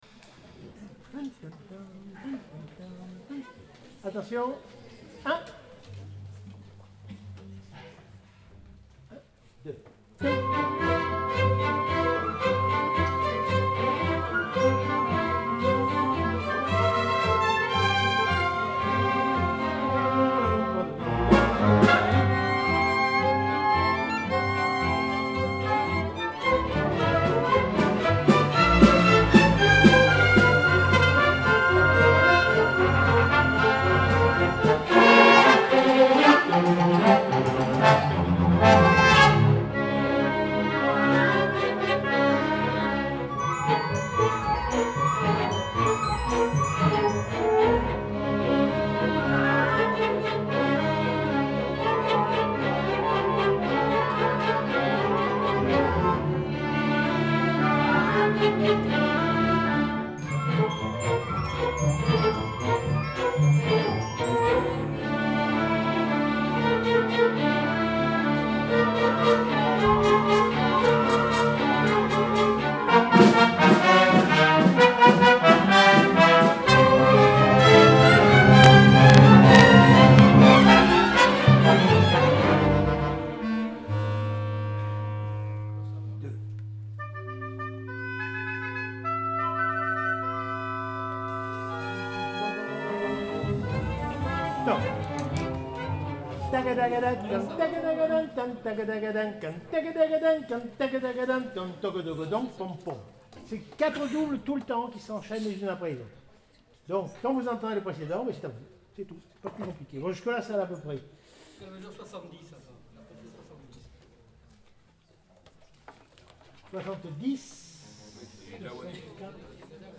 répétition